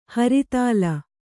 ♪ haritāla